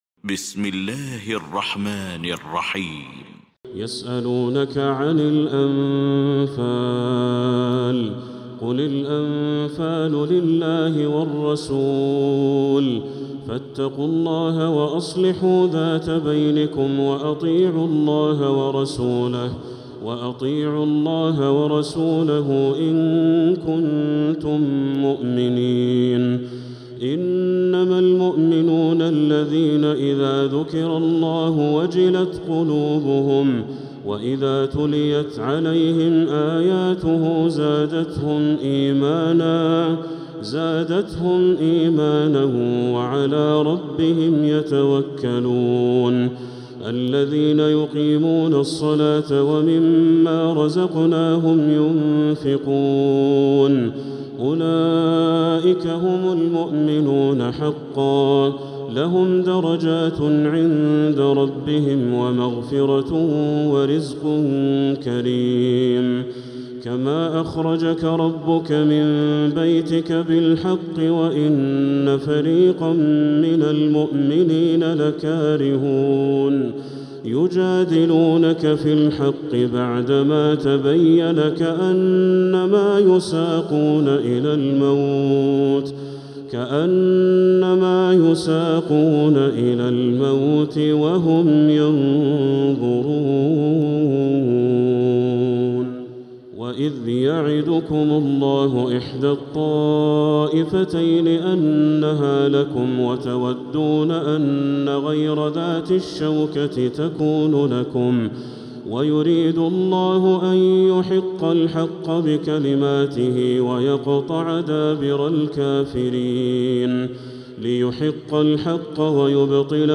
سورة الأنفال | مصحف تراويح الحرم المكي عام 1446هـ > مصحف تراويح الحرم المكي عام 1446هـ > المصحف - تلاوات الحرمين